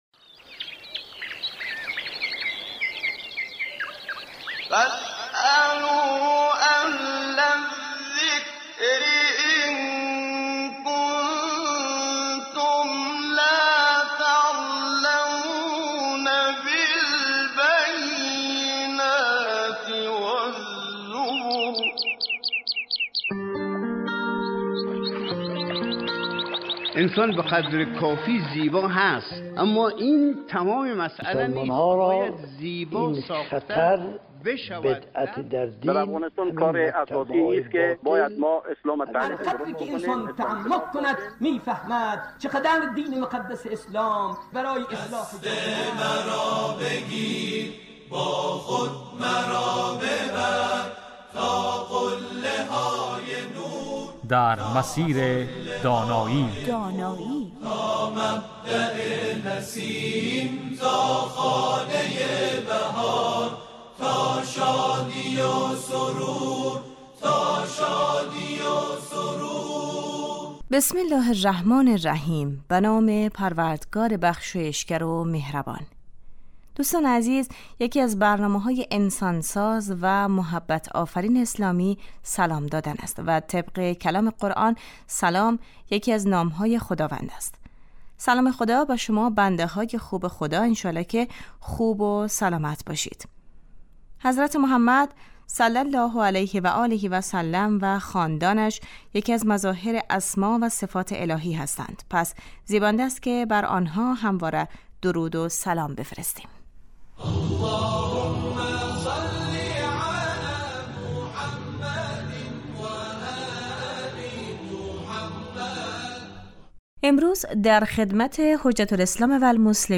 در این برنامه در سه روز اول هفته در مورد تعلیم تربیت کودک و نوجوان از دیدگاه اسلام و در سه روز پایانی هفته در مورد مسایل اعتقادی و معارف اسلامی بحث و گفتگو می شود . این برنامه 20 دقیقه ای هر روز بجز جمعه ها ساعت 11:35 از رادیو دری پخش می شود